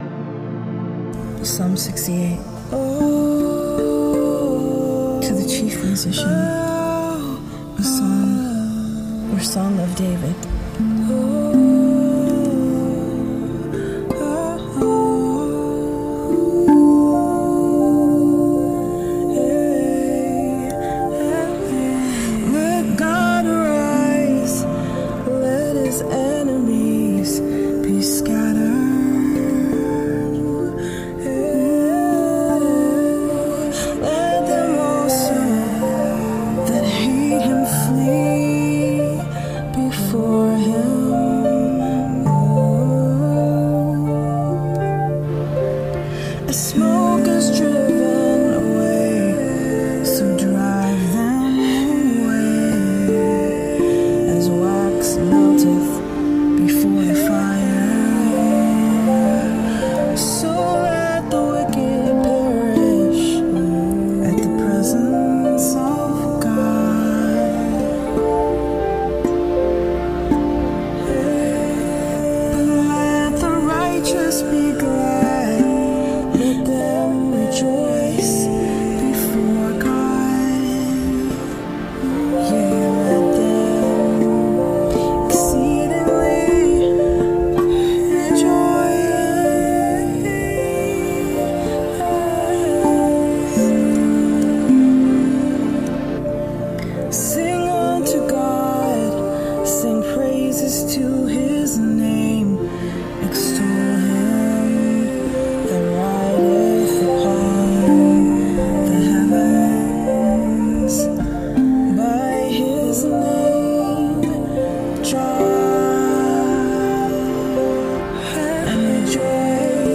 Psalm 68 Rav Vast & Beats Sessions